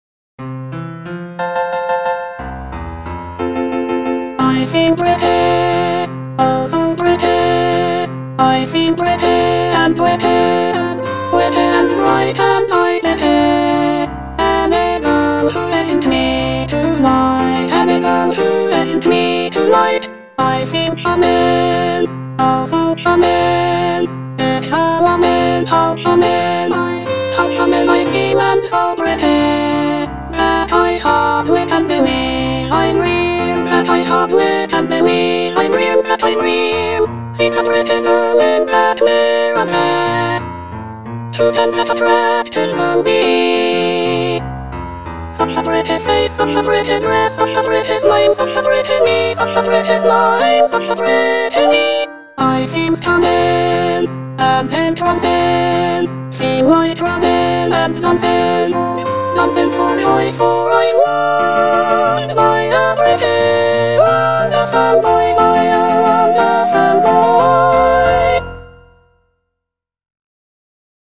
Choral selection
(soprano II) (Emphasised voice and other voices)